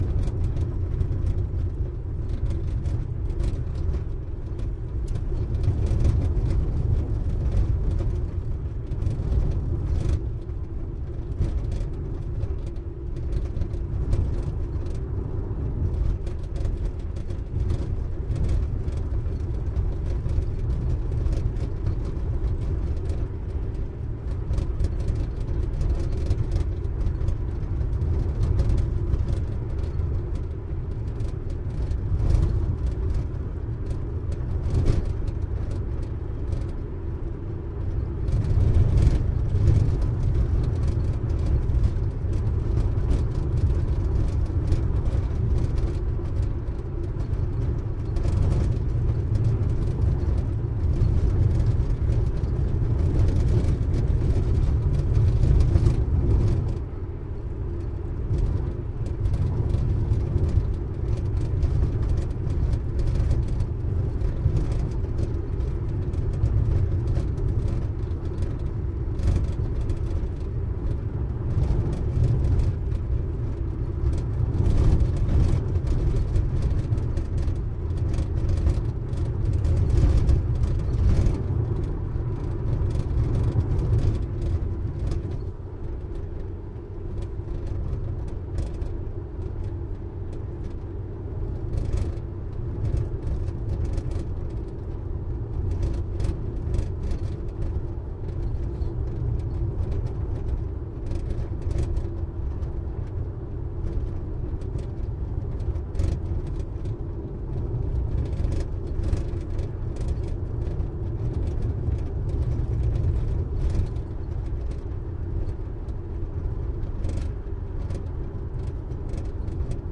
自动驾驶汽车嘎嘎作响的颠簸路面40K F
Tag: 颠簸 40kph rattly 自动